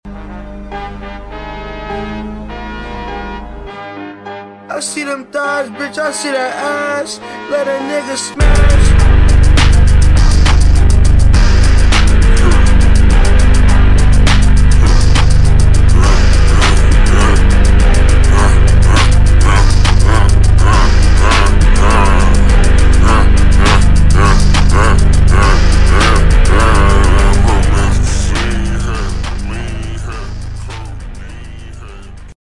Satisfying audio settings Ui volume sound effects free download